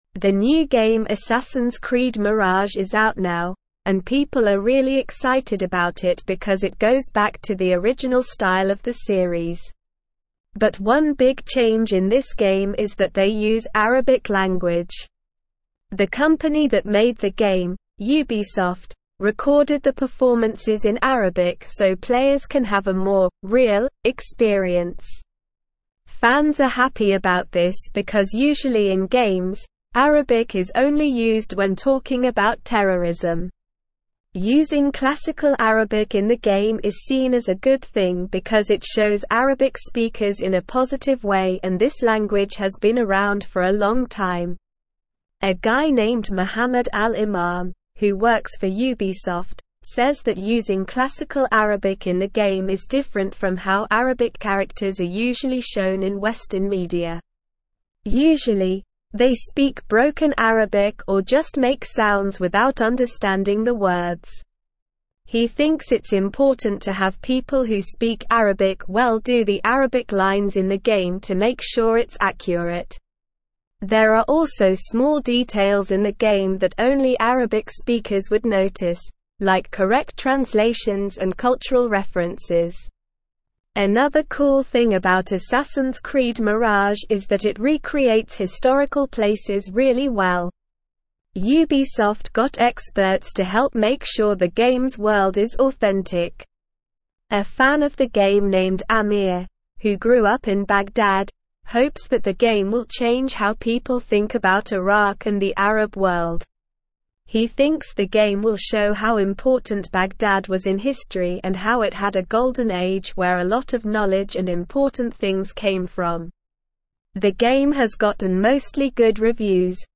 Slow
English-Newsroom-Upper-Intermediate-SLOW-Reading-Assassins-Creed-Mirage-Authentic-Arabic-Representation-in-Gaming.mp3